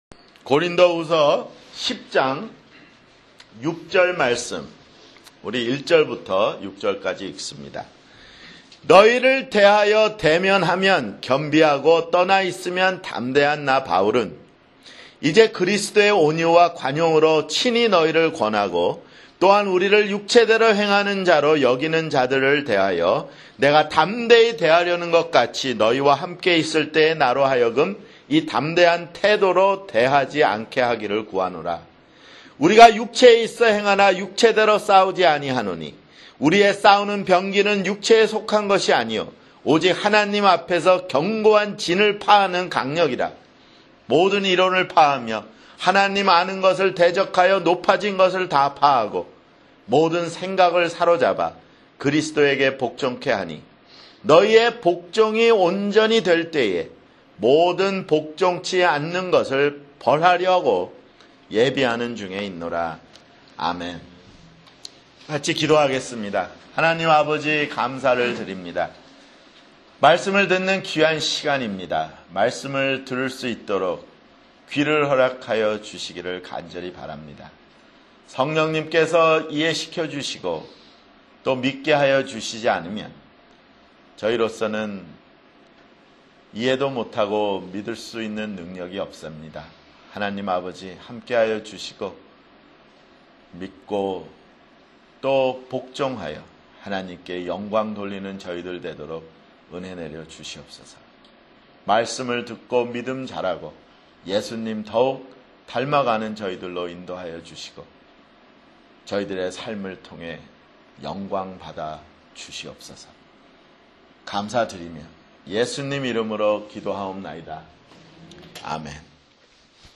[주일설교] 고린도후서 (48)